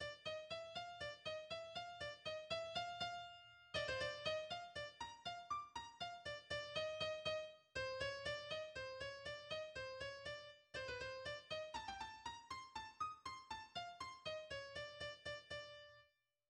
変ホ長調 、8分の3拍子、 変奏曲形式 。